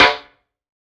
SNARE 046.wav